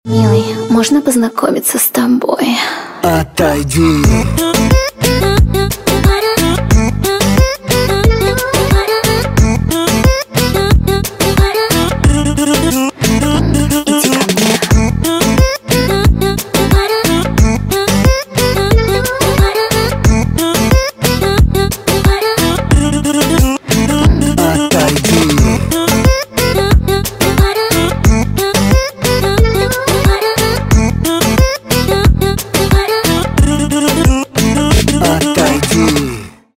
• Качество: 256, Stereo
поп
dance
голосовые